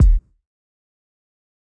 JJKicks (3).wav